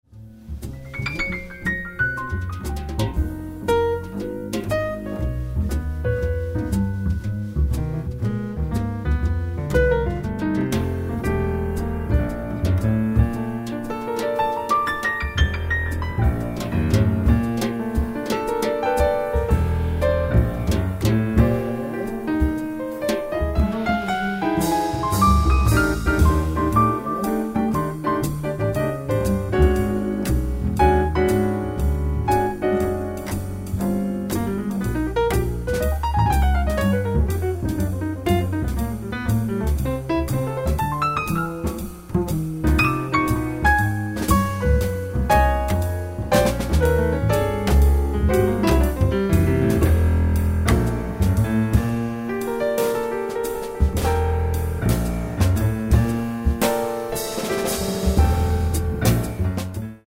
drums
acoustic bass